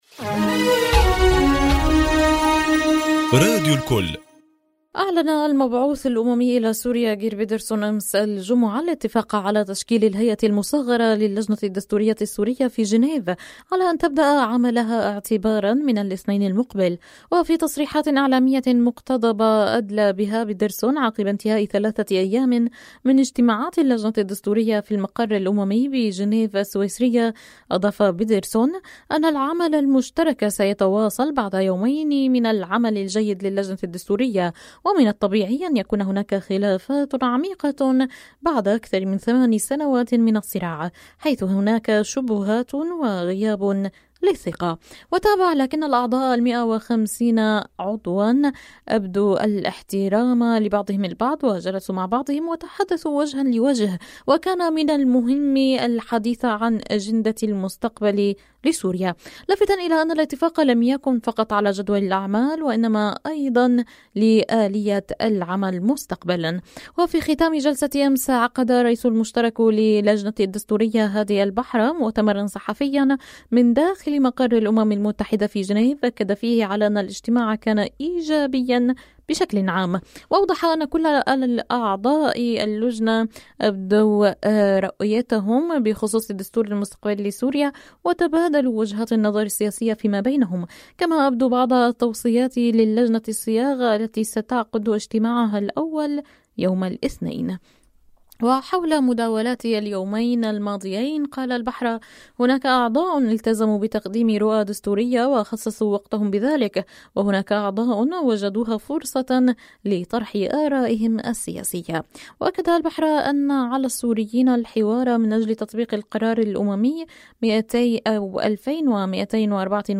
تسجيل حوار إذاعي في راديو الكل حول الموضوع نفسه